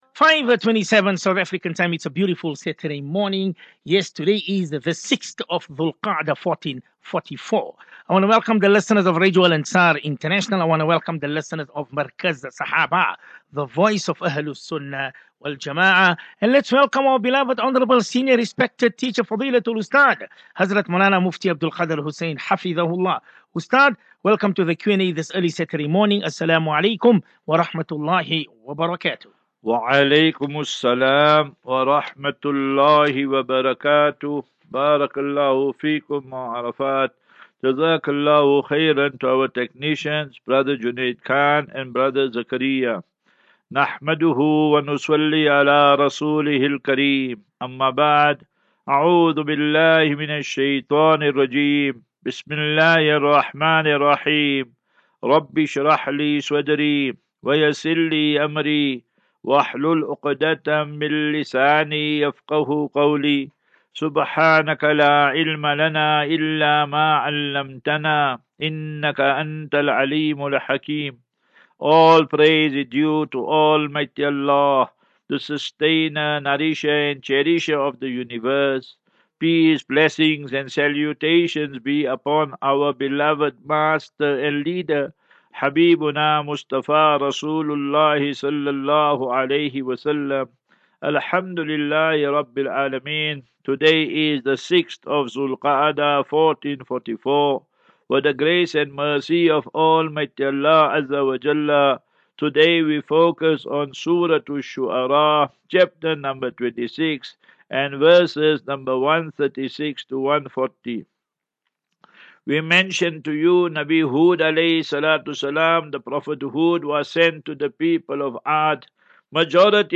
As Safinatu Ilal Jannah Naseeha and Q and A 27 May 27 May 23 Assafinatu